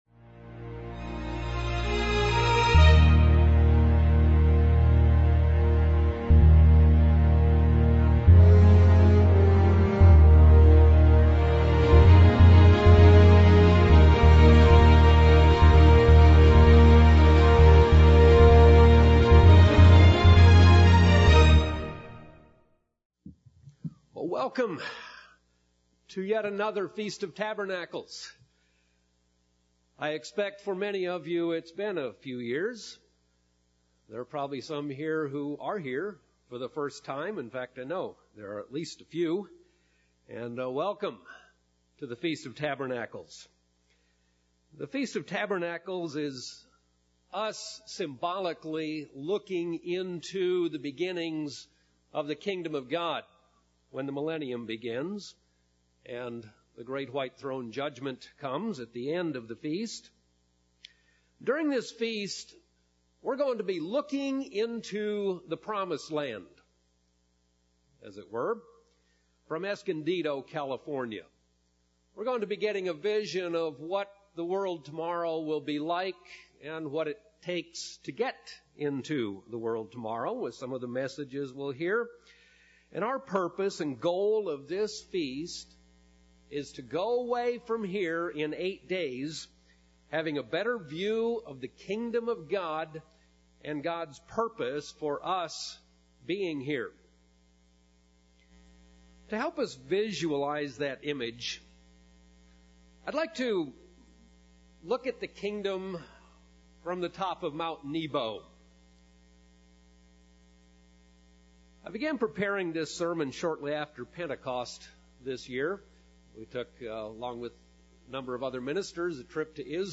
The Kingdom of God is coming–do you have the vision, what is that vision, and are you passing it on? (2010 Feast of Tabernacles sermon from Escondido, California)